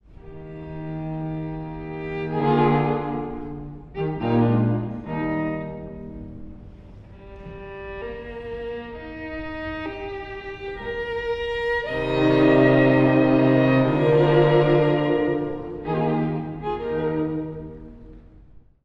古い音源なので聴きづらいかもしれません！（以下同様）
第3楽章｜長調なのに、どこか切ない
明るい調性なのに、どこか哀しさのにじむ緩徐楽章。
「偽終止」という、やわらかく終わる技法が多く使われています。
聴き手の予想をやさしく裏切るような終わり方が、独特の味わいを生んでいます。